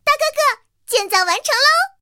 T-127建造完成提醒语音.OGG